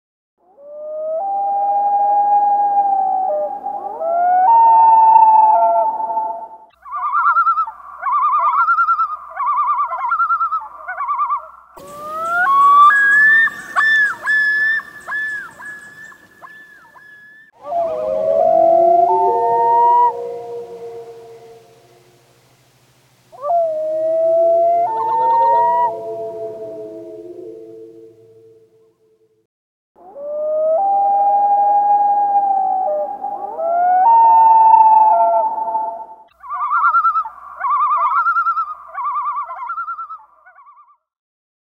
Schrei des Loon [1.629 KB] - mp3
cryoftheloon2cut320.mp3